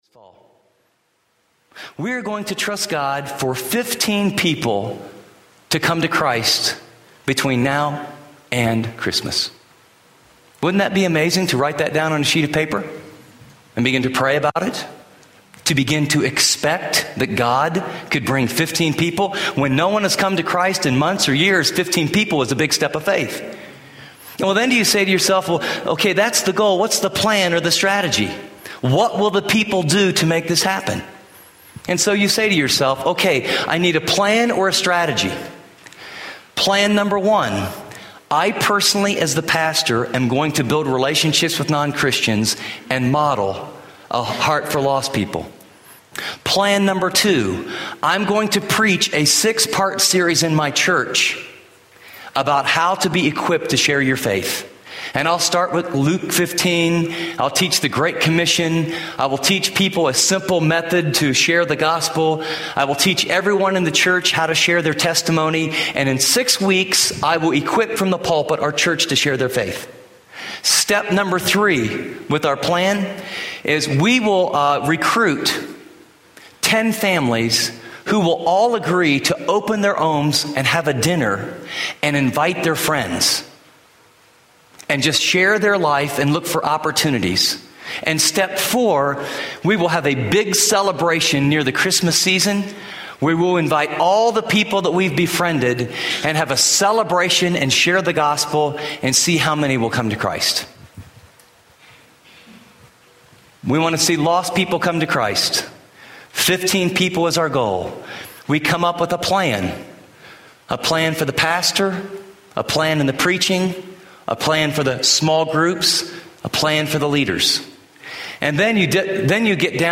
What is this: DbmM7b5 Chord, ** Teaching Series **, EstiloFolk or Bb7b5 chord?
** Teaching Series **